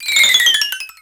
Cri de Crikzik dans Pokémon X et Y.